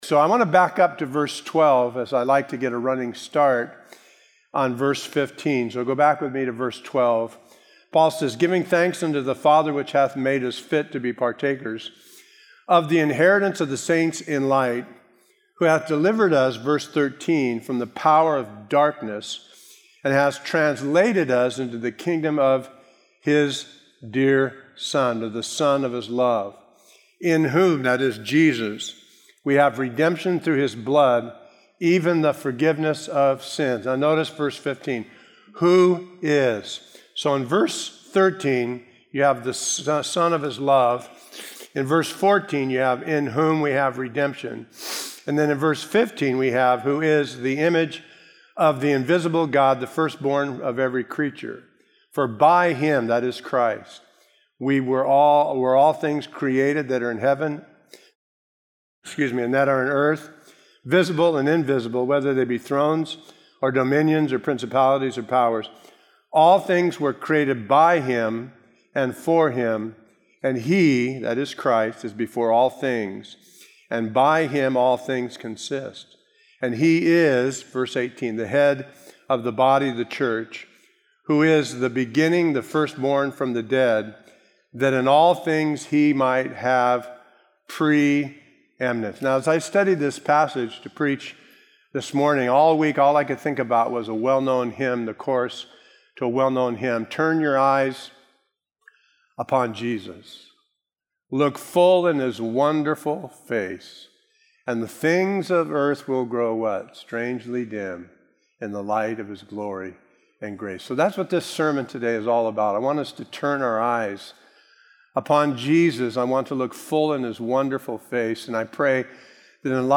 A verse-by-verse expository sermon through Colossians 1:15-18